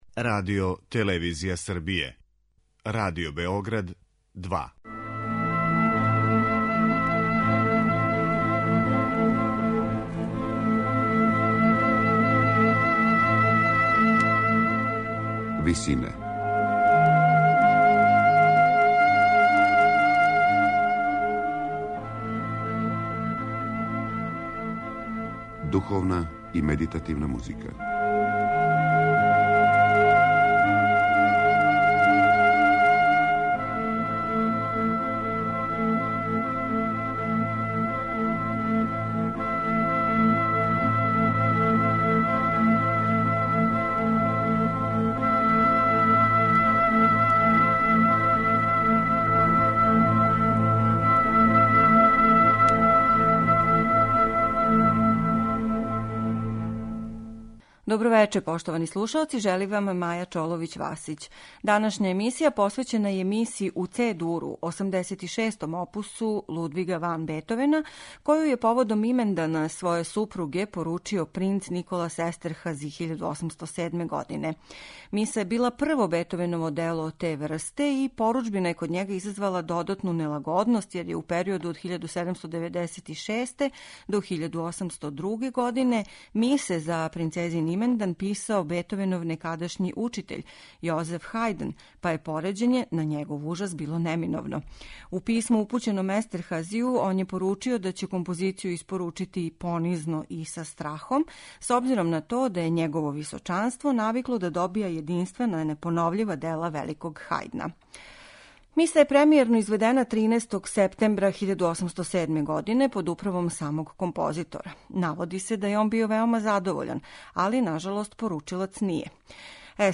Миса представља индивидуалан начин интерпретације и реализације ове музичке форме, а примера за то има у свим сегметнима компоновања, као што су третман текста, употреба солистичких гласова или хорских деоница.